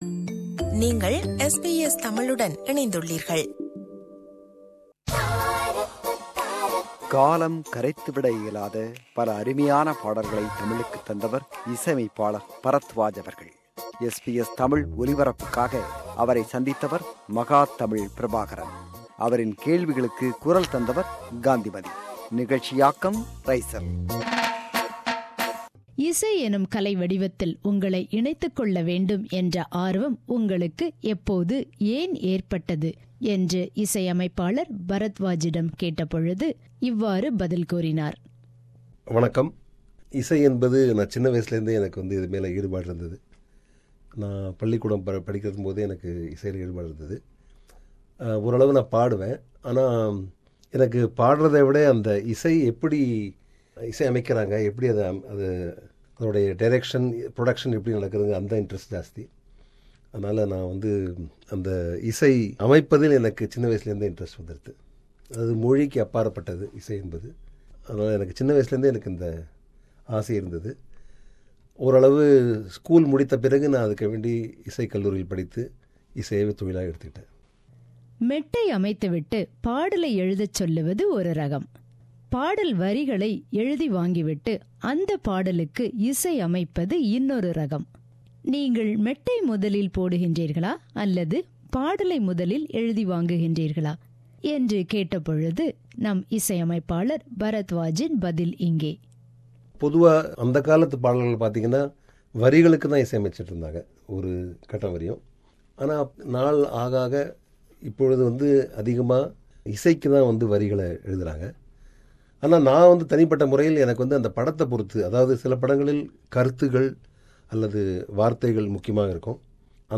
Interview with Music Director Barathwaj – Part 1